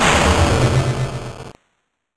bomb.wav